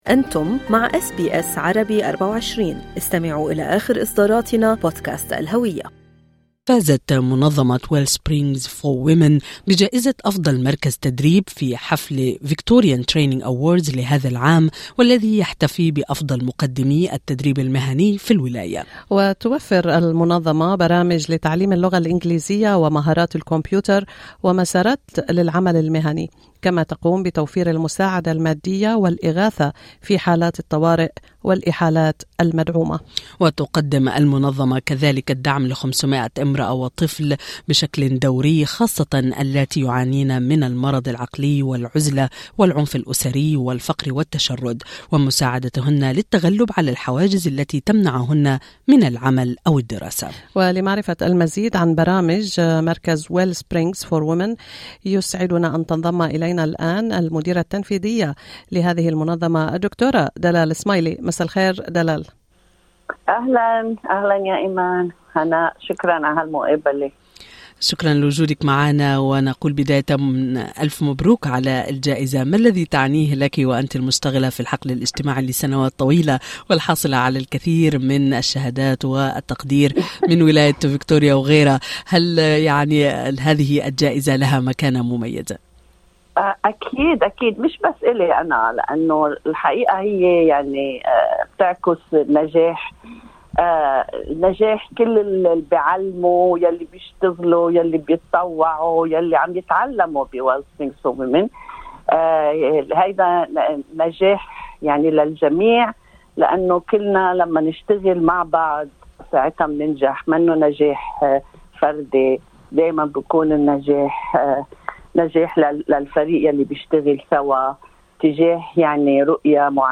استمعوا إلى اللقاء كاملا في المدونة الصوتية في أعلى الصفحة.